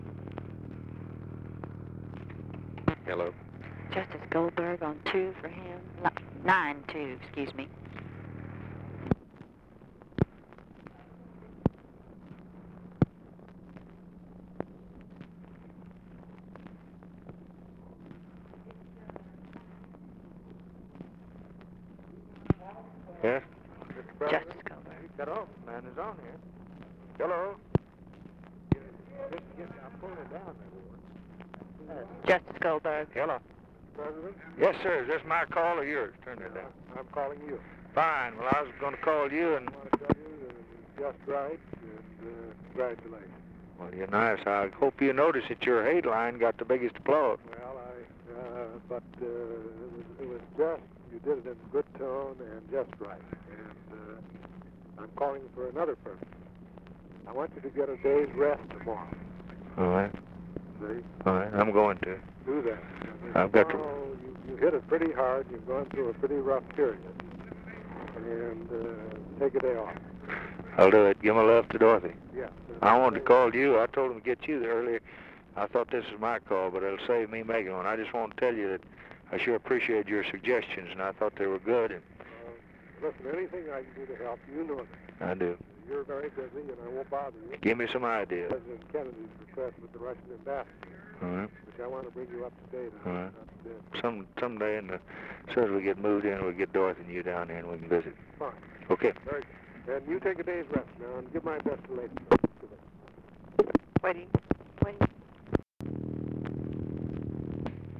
Conversation with ARTHUR GOLDBERG, November 27, 1963
Secret White House Tapes